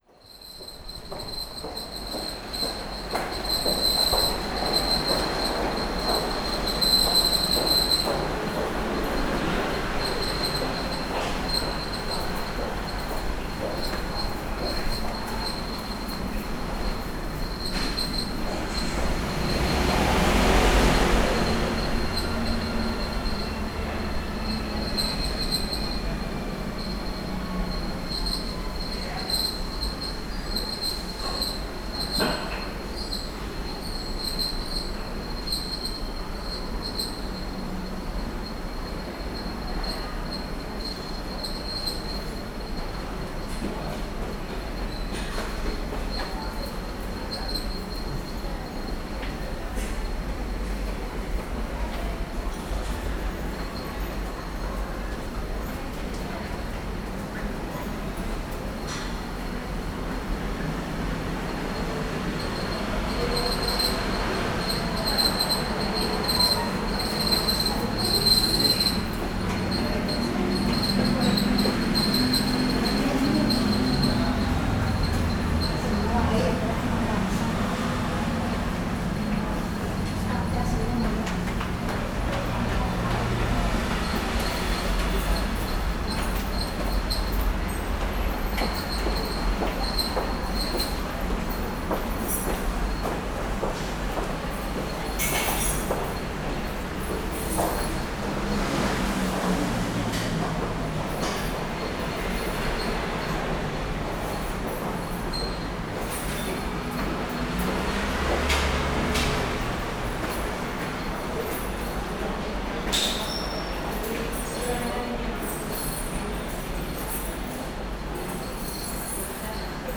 En la entrada de los Ferrocarrils de la Generalitat del barrio de Gràcia de Barcelona, a veces la fuga de la nevera de bebidas, la gente que coge las bicis, el viento y la gente que habla, suena de esta manera.
[ENG] At the entrance of the railways of Gràcia, sometimes leak from the fridge of drinks, people grab bikes, wind and people talking, sounds like this.
Zoom H6 with MSH-6 MS